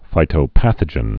(fītō-păthə-jən)